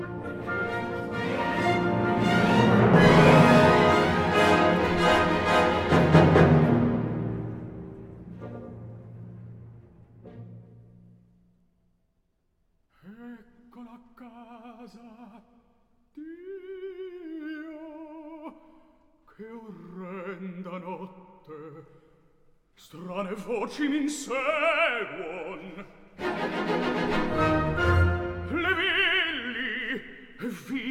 le ténor